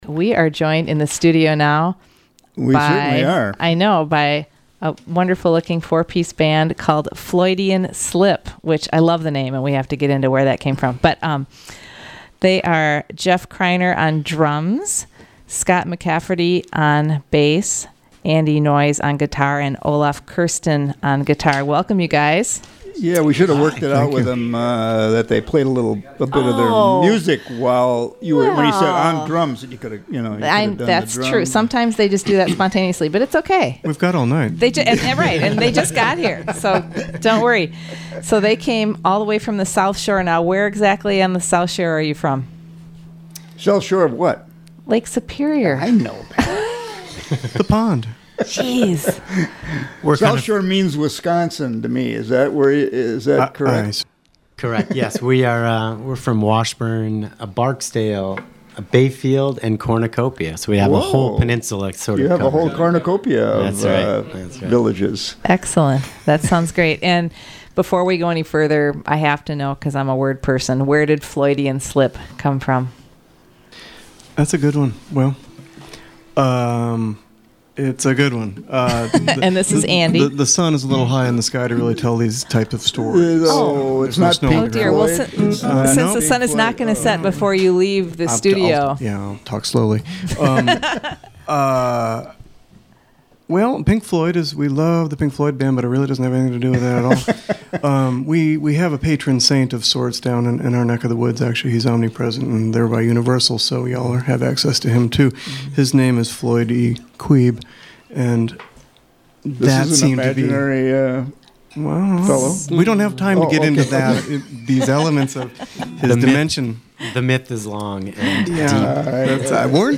bass
guitar
drums
Studio A
fun conversation and great original music & covers